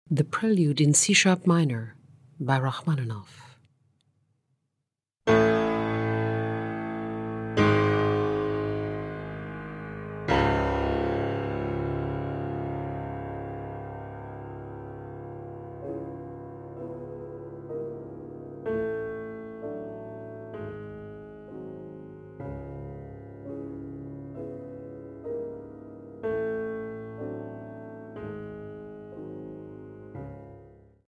Track 21: Prelude in C-sharp Minor